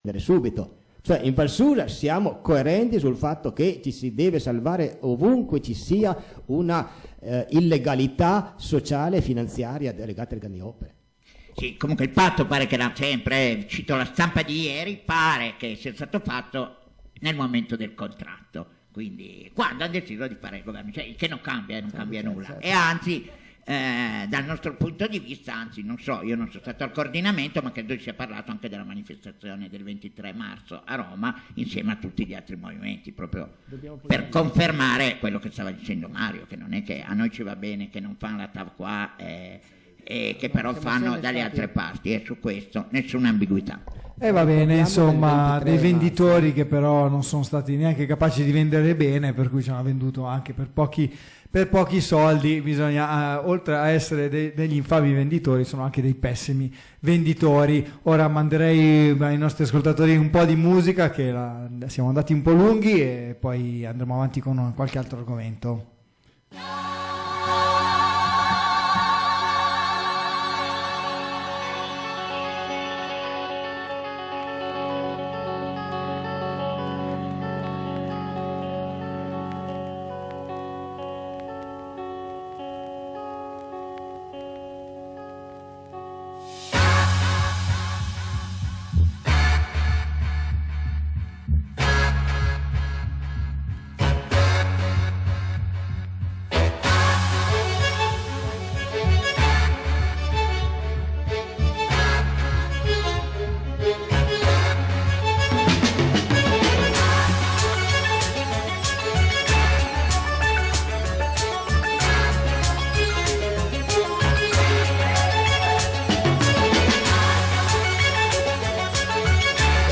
Puntata speciale – Live dalla giornata antiproibizionista “Support. Don’t Punish” tenutasi a Collegno al Parco della Certosa